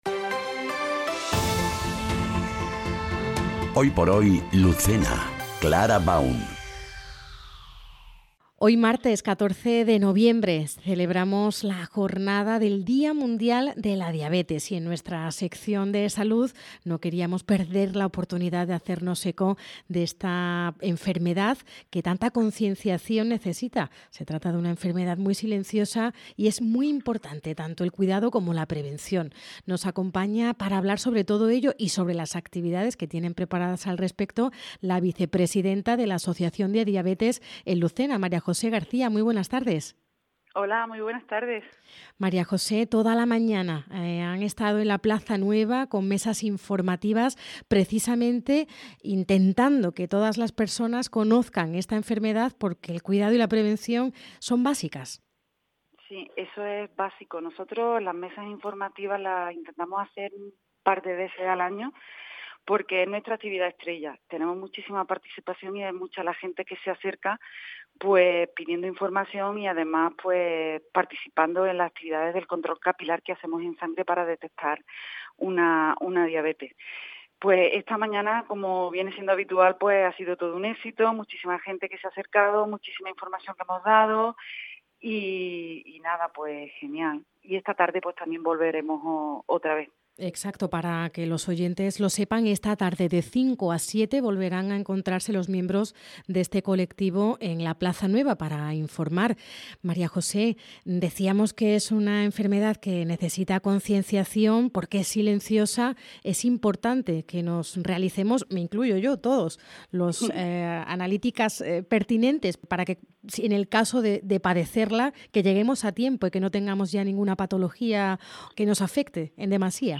ENTREVISTA | Jornada Día Mundial de la Diabetes En Lucena